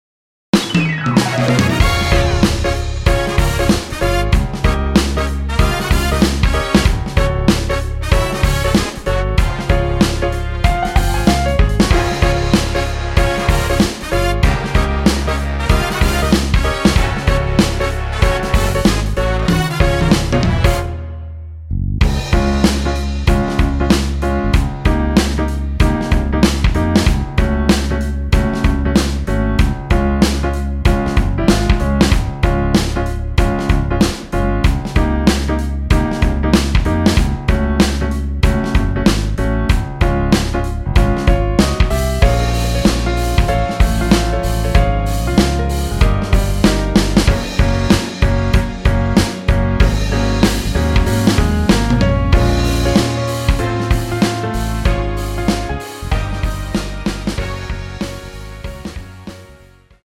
원곡보다 짧은 MR입니다.(아래 재생시간 확인)
원키에서(-1)내린 (1절앞+후렴)으로 진행되는 MR입니다.
Db
앞부분30초, 뒷부분30초씩 편집해서 올려 드리고 있습니다.
중간에 음이 끈어지고 다시 나오는 이유는